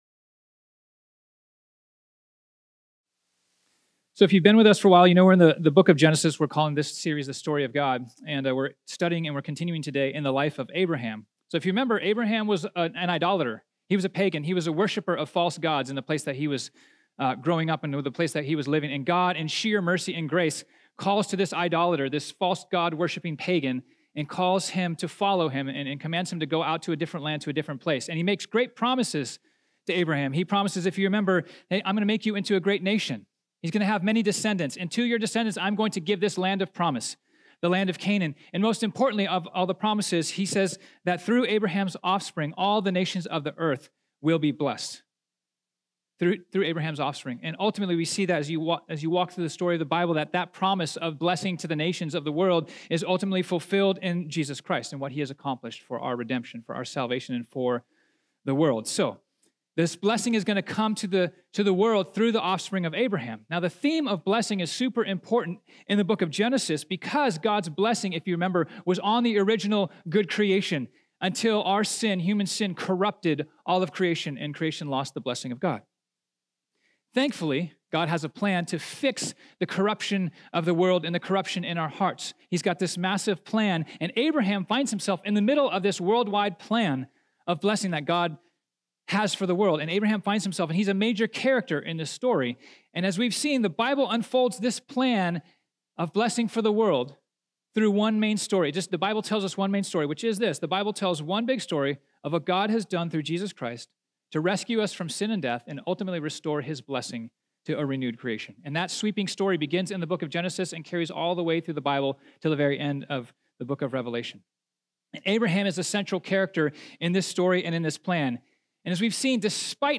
This sermon was originally preached on Sunday, March 10, 2019.